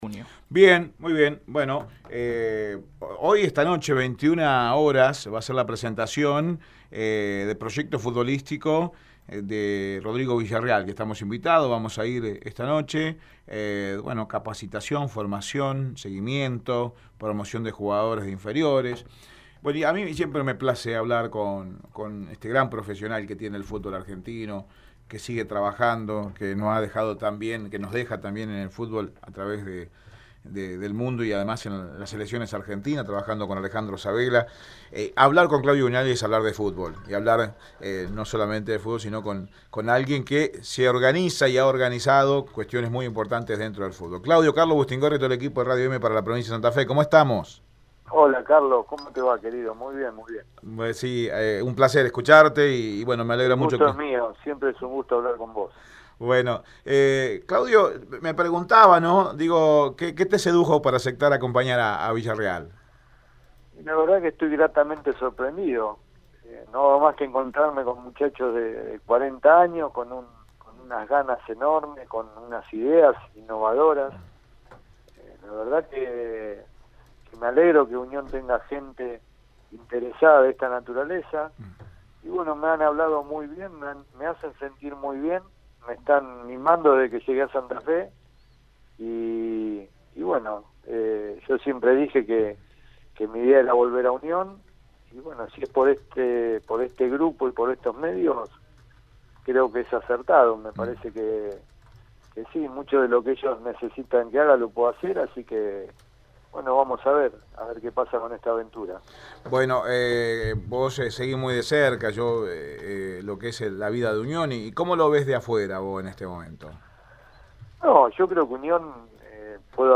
En diálogo exclusivo con Radio Eme Deportivo